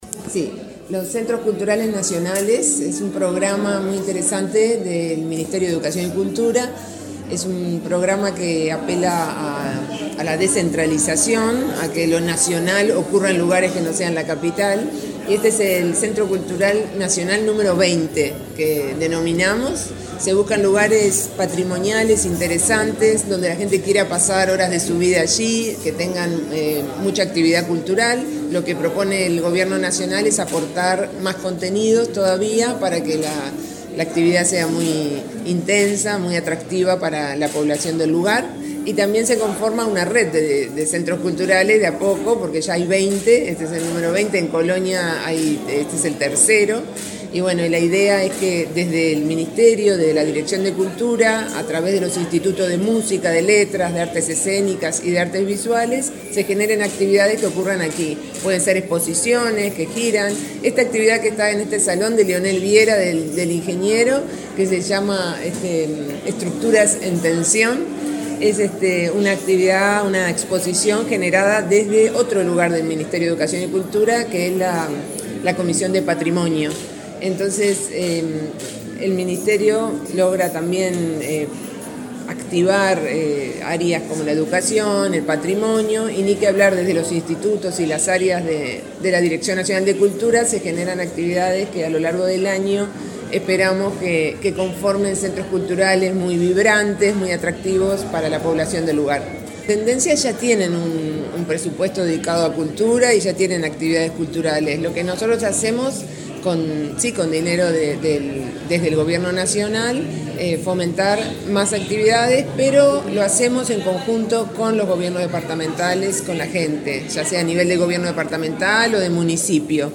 Entrevista a la directora nacional de Cultura
La titular de la dependencia, Mariana Wainstein, dialogó con Comunicación Presidencial acerca del significado de esta práctica.